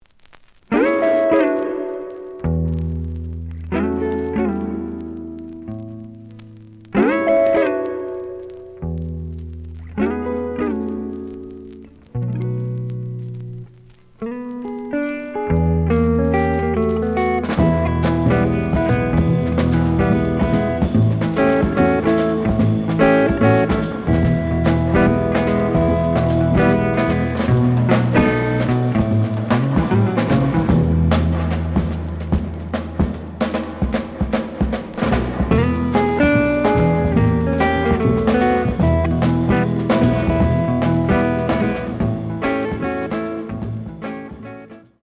The audio sample is recorded from the actual item.
●Genre: Modern Jazz
Looks good, but slight noise on both sides.)